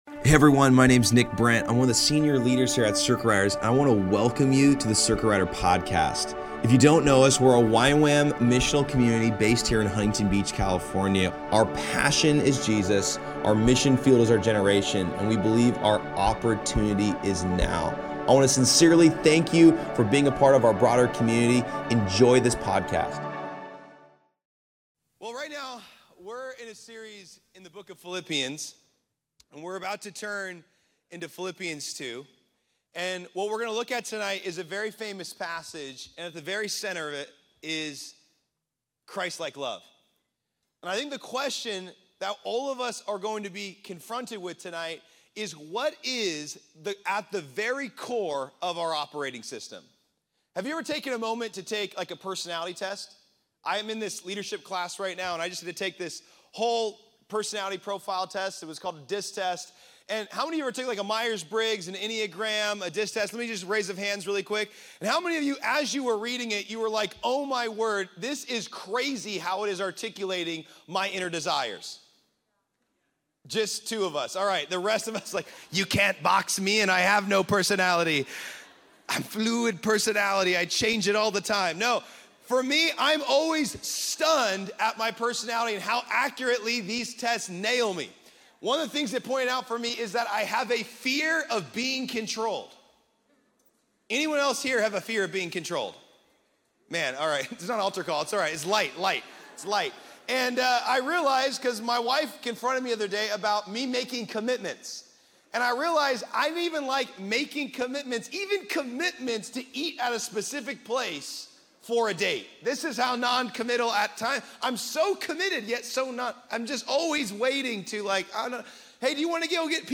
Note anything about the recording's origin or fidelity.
Location Costa Mesa